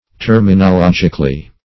-- Ter`mi*no*log"ic*al*ly , adv.